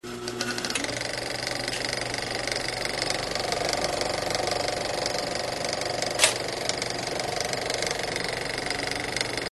Звуки пленки
Звук старого кинопроектора